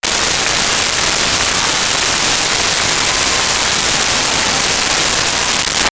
Applause Whistle
APPLAUSE-WHISTLE.wav